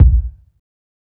KICK_DONT_FUCK_WIT_ME.wav